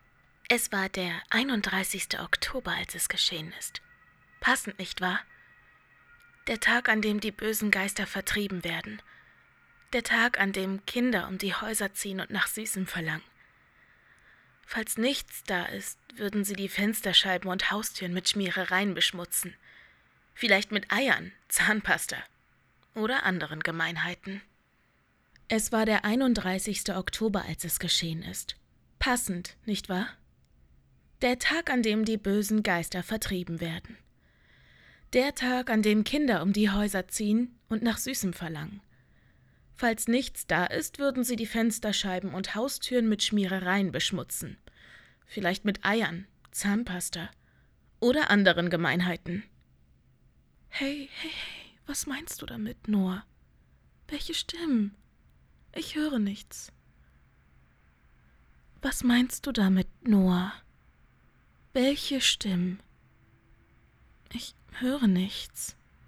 Ist nur schnell am Laptop gemacht und der Mik-Anschluss brummt da leider... falls du es nehmen möchtest, würde ich es aber noch mal sauber in der Studiokammer aufnehmen ). Flüsterer FX your_browser_is_not_able_to_play_this_audio Flüsterer DRY your_browser_is_not_able_to_play_this_audio